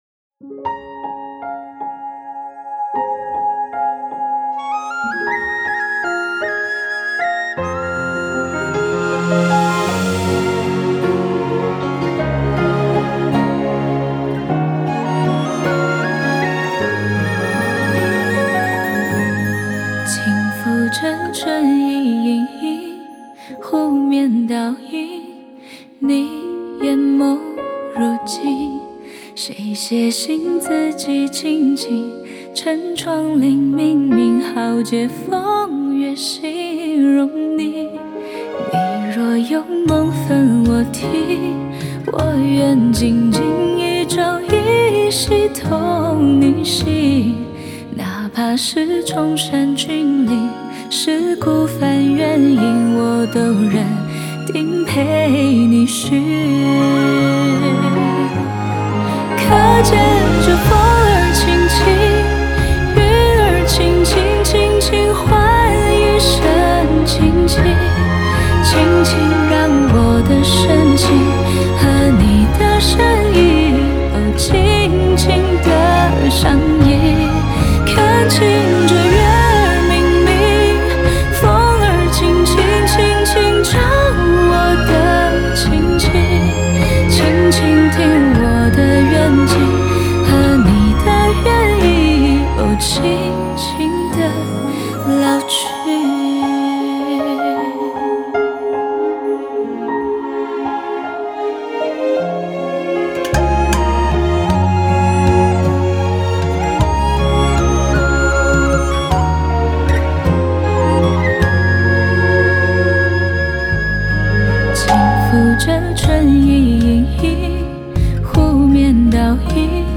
Ps：在线试听为压缩音质节选，体验无损音质请下载完整版
弦乐
吉他/贝斯
笛子